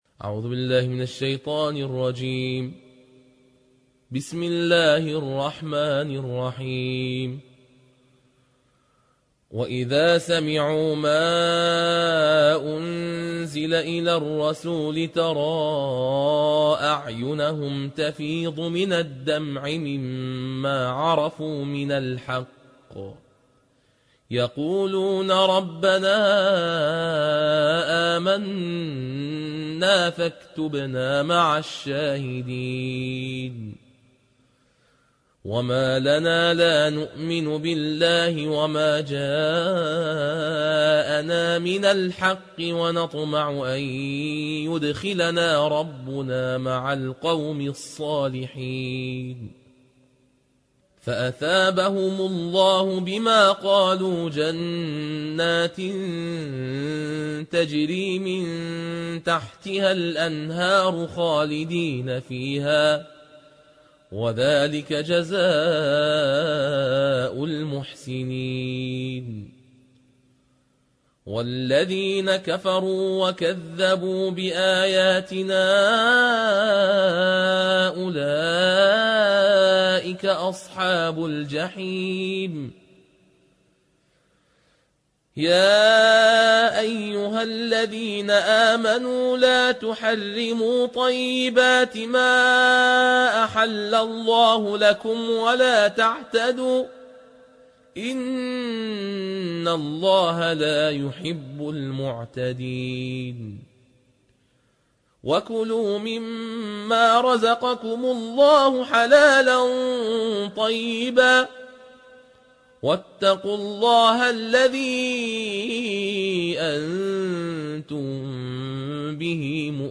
الجزء السابع / القارئ